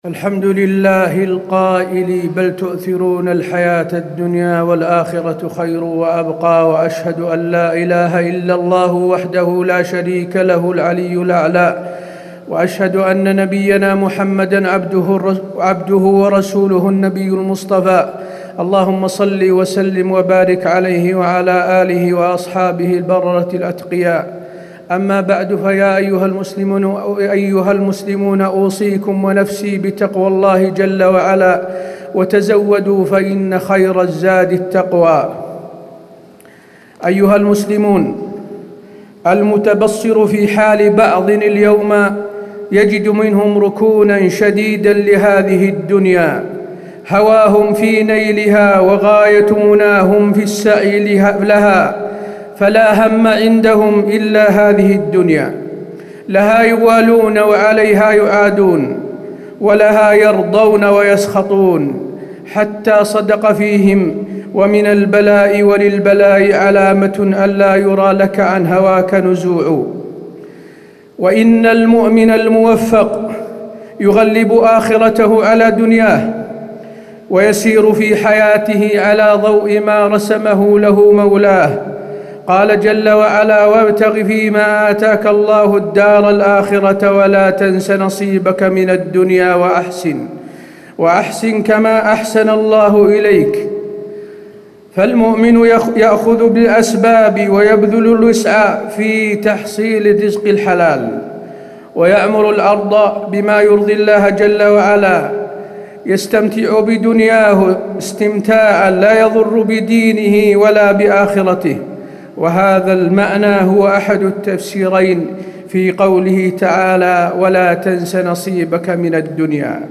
تاريخ النشر ٣٠ جمادى الأولى ١٤٣٩ هـ المكان: المسجد النبوي الشيخ: فضيلة الشيخ د. حسين بن عبدالعزيز آل الشيخ فضيلة الشيخ د. حسين بن عبدالعزيز آل الشيخ اجعل الآخرة همك